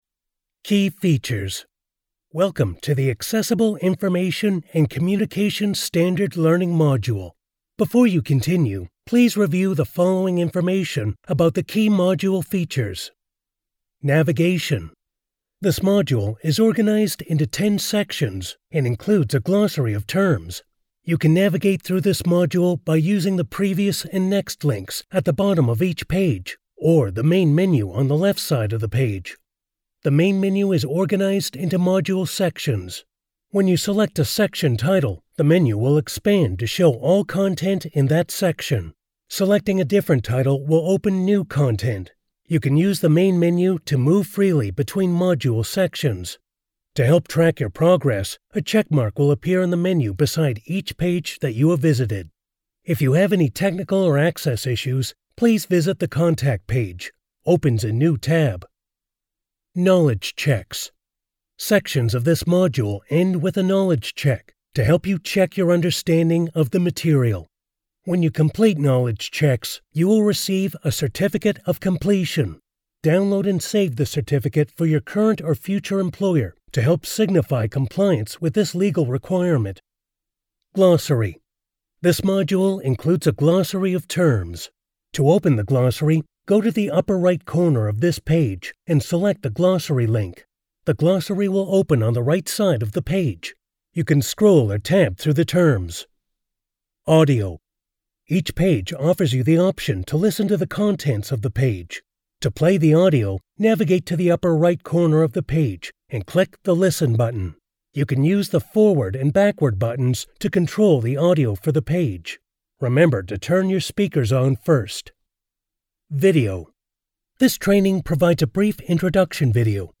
Listen Back Play Pause Stop Forward 00:00 00:36 Mute Unmute Audio narration.